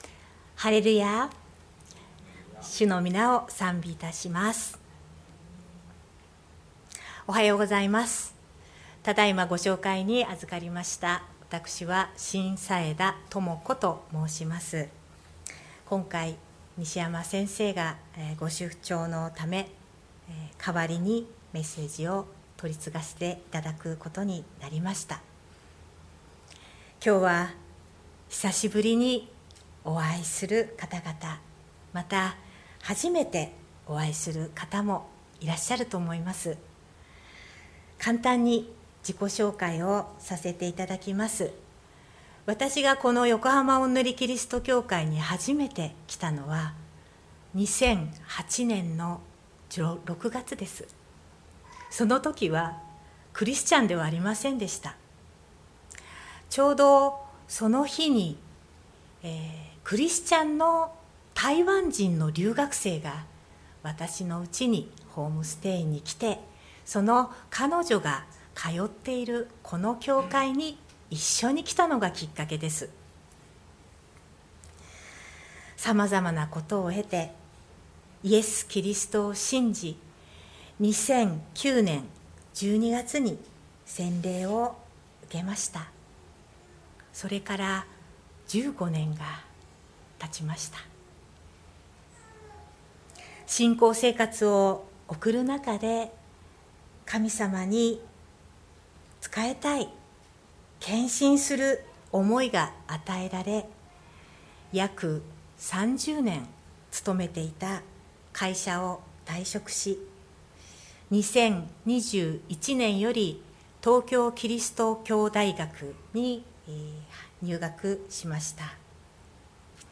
横浜オンヌリキリスト教会の説教を配信します。